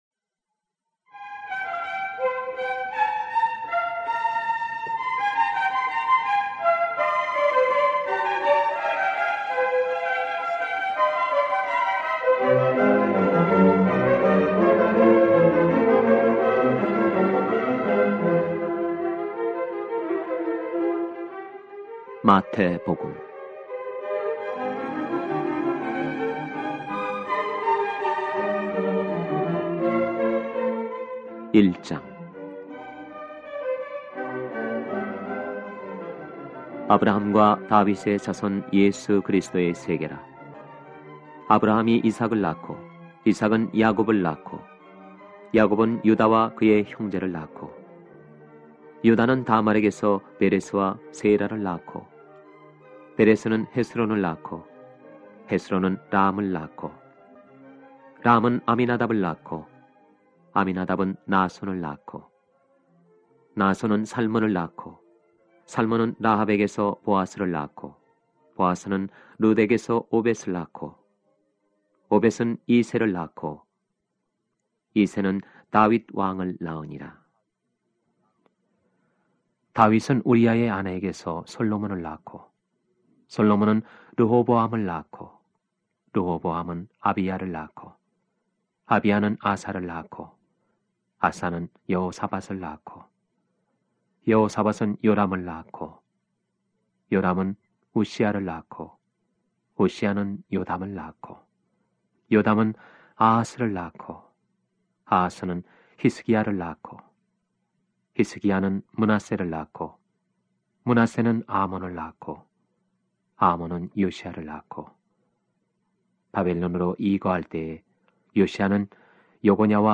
신.구약 DD성경은 성경 말씀 그대로 국내 정상급 믿음의 성우들이 출연하여 낭독한 최첨단 성경입니다.